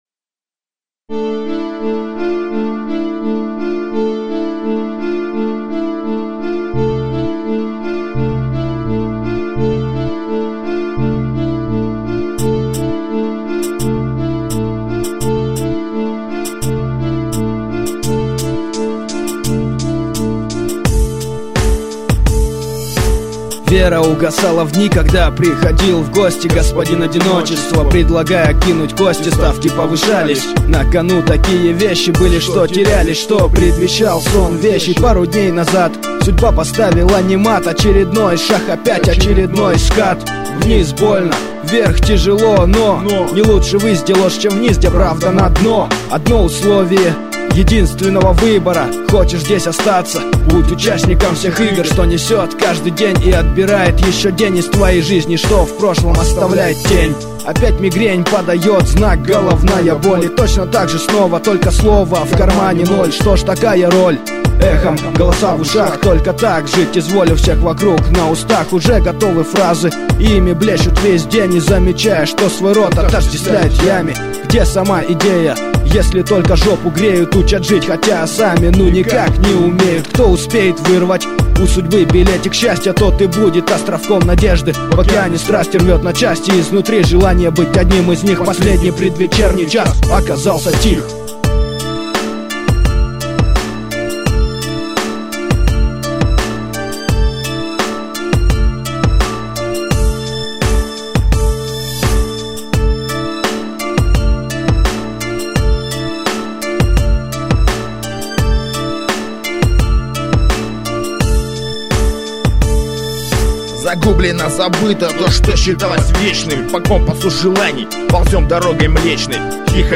undeground rap
пересведенный трек